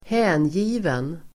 Uttal: [²h'ä:nji:ven]